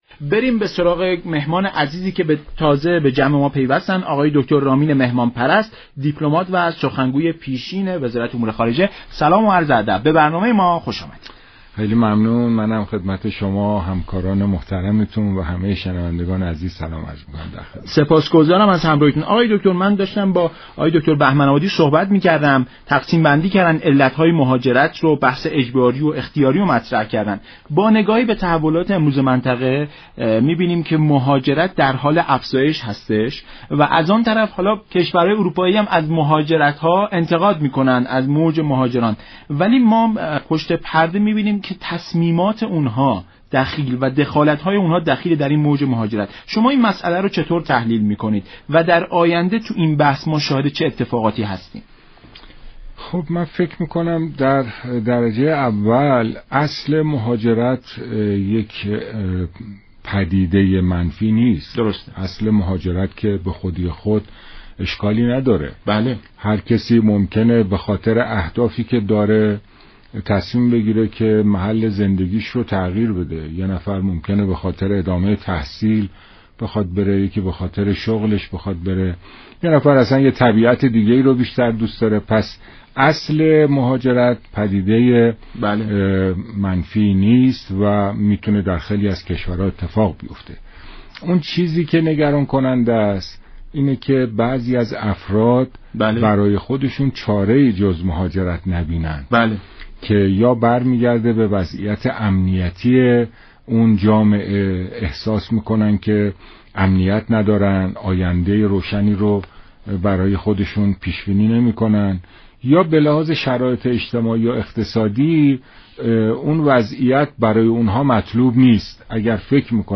سخنگوی پیشین وزارت امور خارجه در برنامه ایران امروز گفت: بسیاری از ایرانیانی كه به دیگر كشورها مهاجرت كرده‌اند؛ برای زندگی بهتر بوده است، اغلب آنان جزو اقلیت‌های موفق و سرآمد آن كشورها هستند.